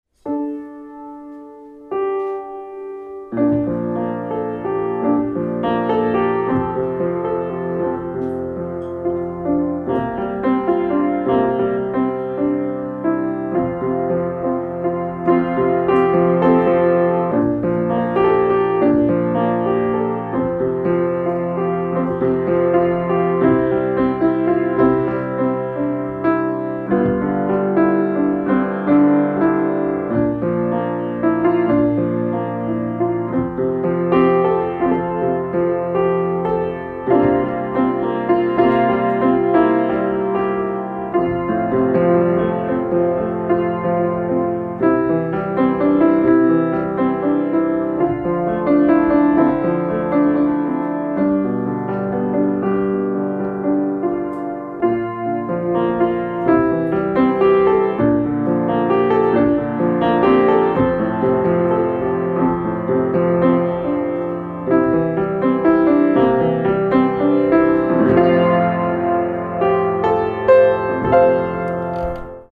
Index of /music/pianoSketches